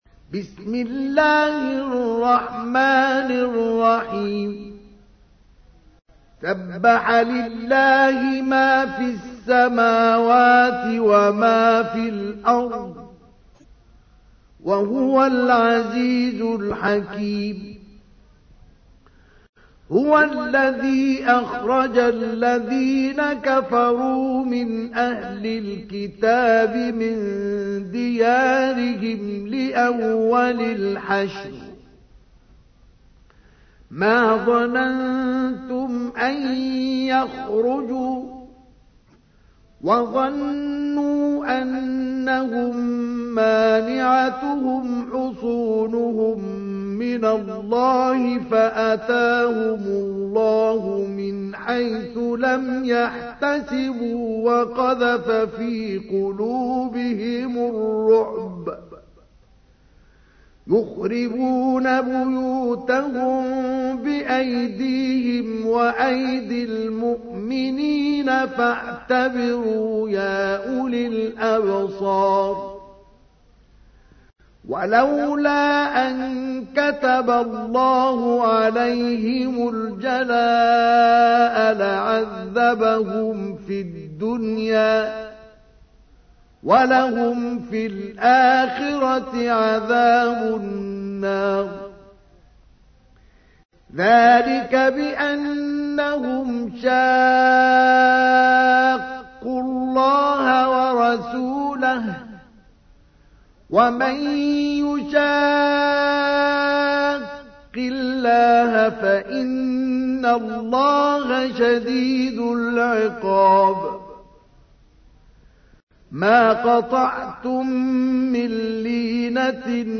تحميل : 59. سورة الحشر / القارئ مصطفى اسماعيل / القرآن الكريم / موقع يا حسين